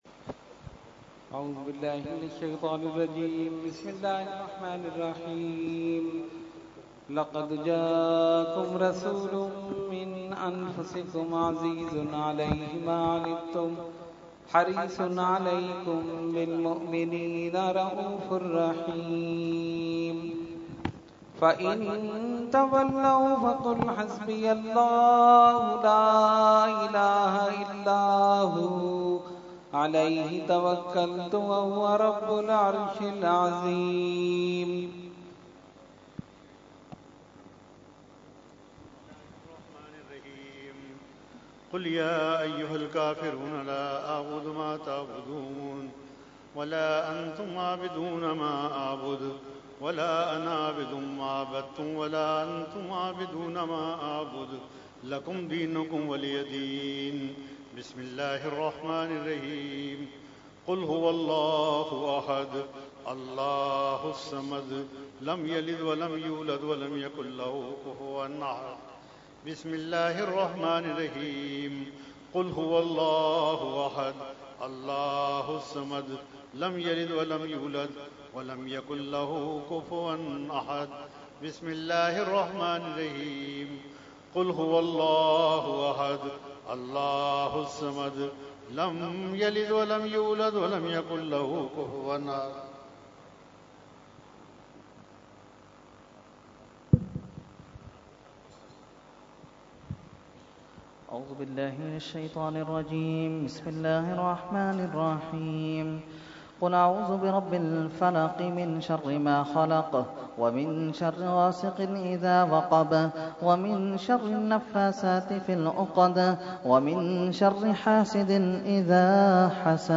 Category : Fatiha wa Dua | Language : ArabicEvent : Urs Makhdoome Samnani 2017